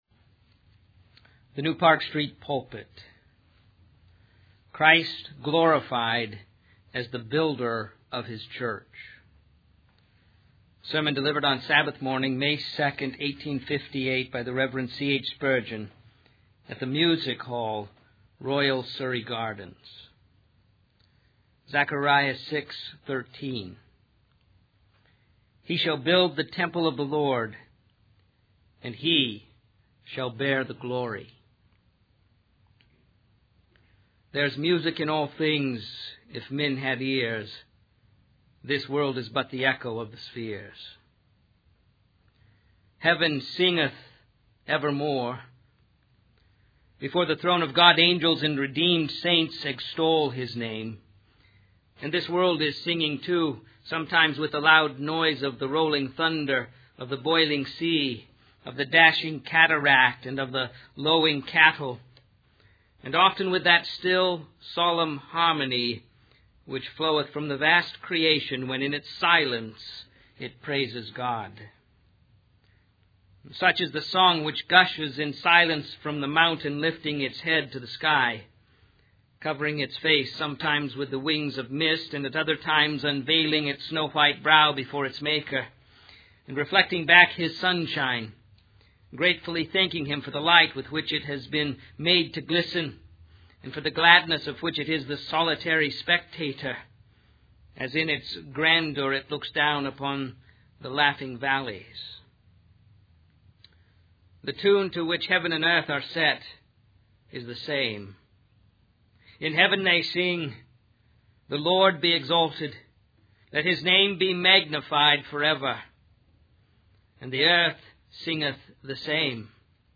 In this sermon, the preacher emphasizes the urgency of choosing to believe in Christ before it is too late. He warns against mocking religion and scoffing at Christ, as the judgment day is approaching.